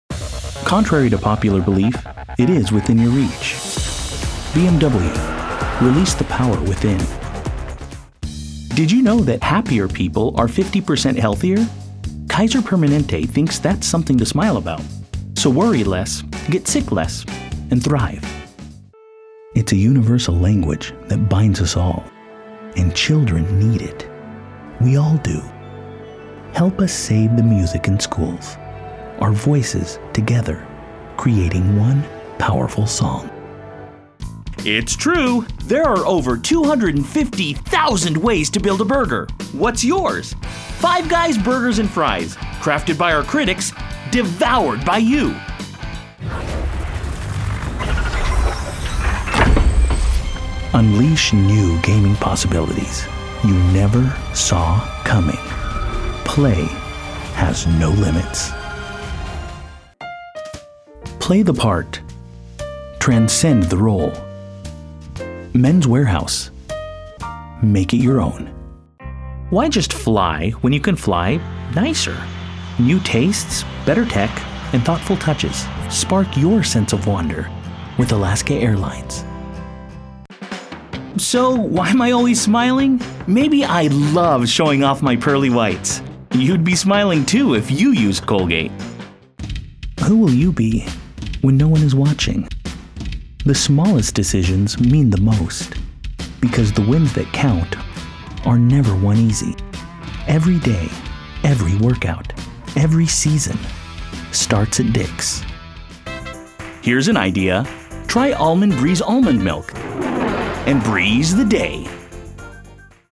Commercial demo
• Friendly guy-next-door;
• Caring, empathetic;
• Professional, business, corporate; instructor;